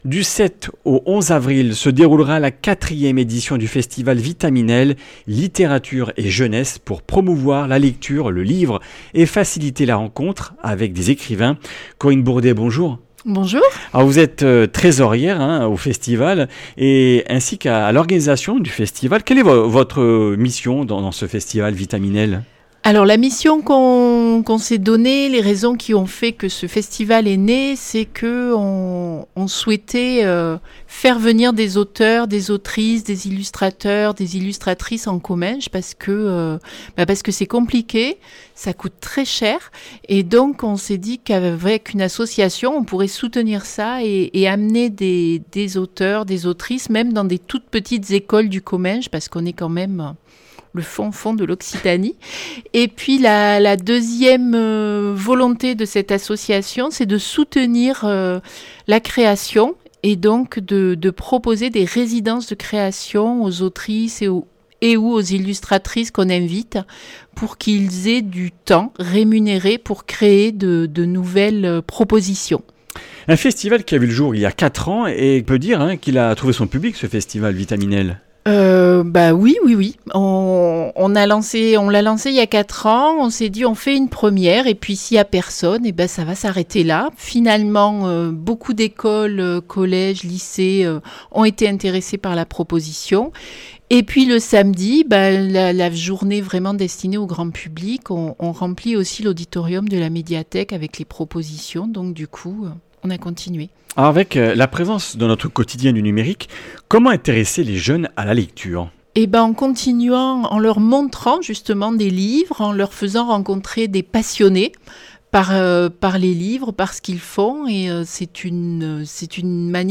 Du 7 au 11 avril se déroulera à Saint-Gaudens la 4ème édition du festival Vitamine L littérature et jeunesse, pour promouvoir la lecture, le livre et faciliter la rencontre avec des écrivains. Interview